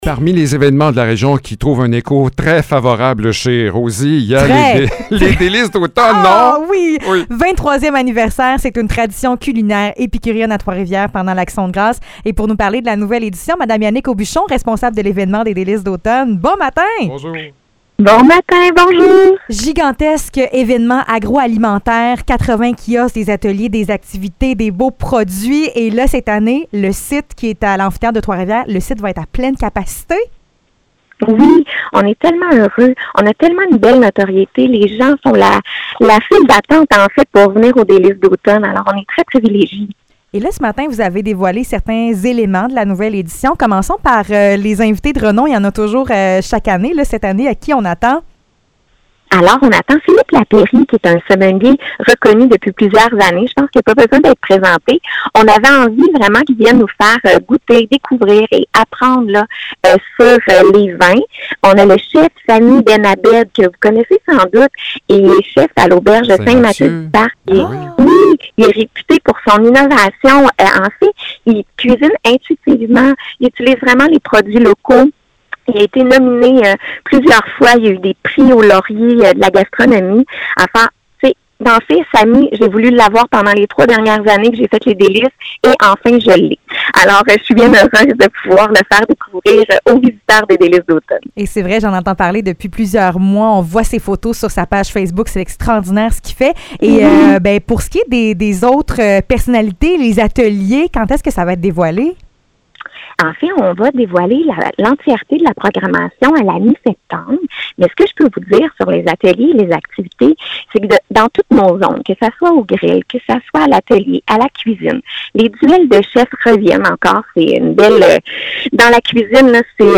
Entrevue : Les Délices d’automne